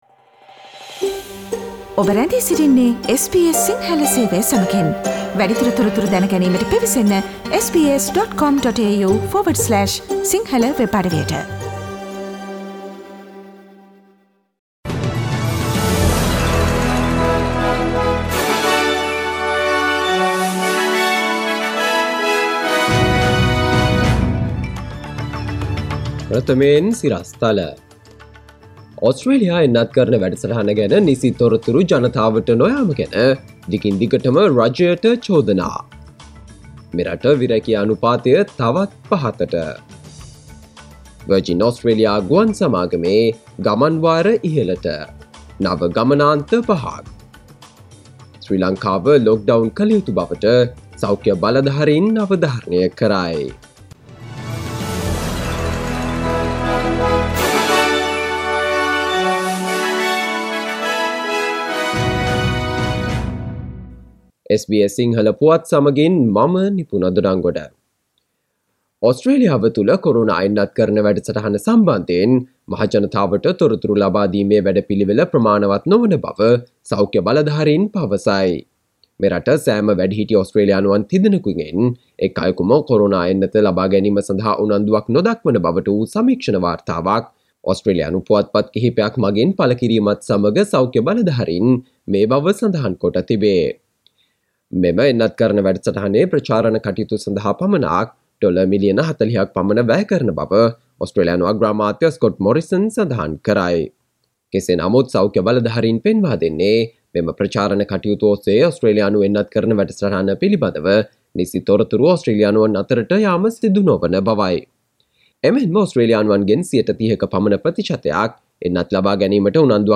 Australia's unemployment rate has fallen again : SBS Sinhala radio news on 21 May 2021
Listen to the latest news from Australia, Sri Lanka, across the globe and the latest news from sports world on SBS Sinhala radio news bulletin – Friday 21 May 2021